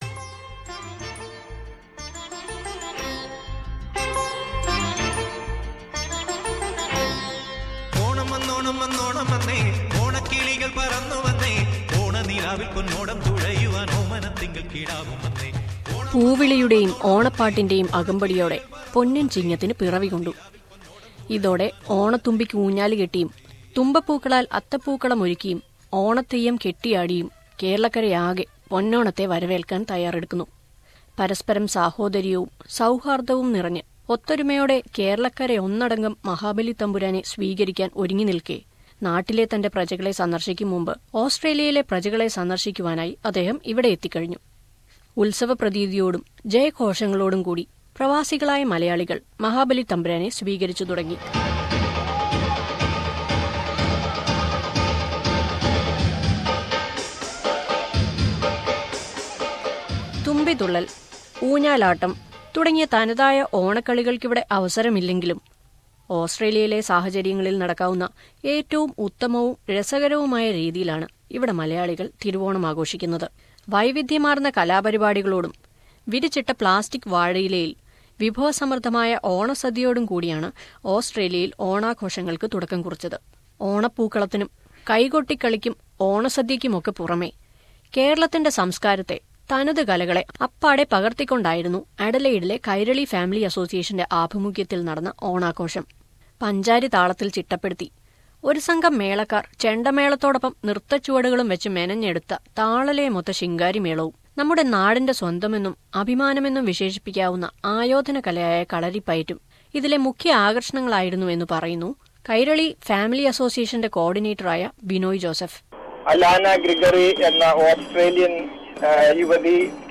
In Australia, the Onam celebrations have begun in the last week of August. Let us listen to a report about the celebrations in various parts of the country.